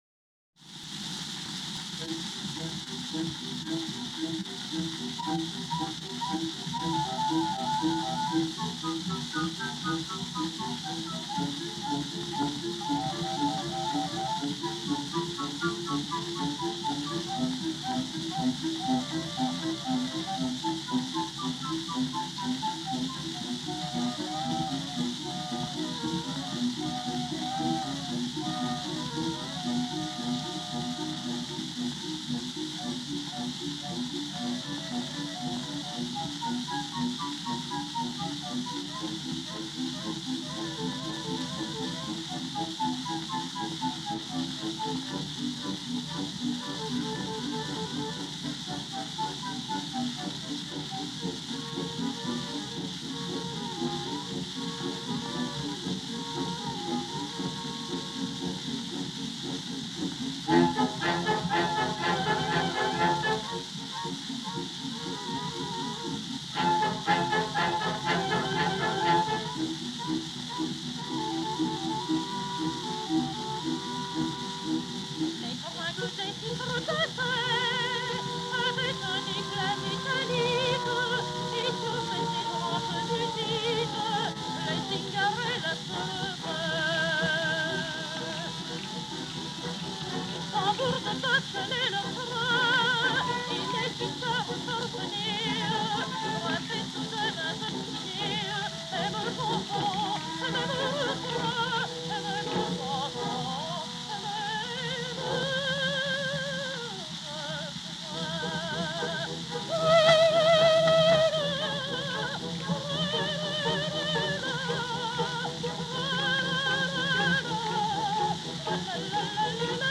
soprano
tenor
baritone
(80rpm, 11½”, edge start, paper label)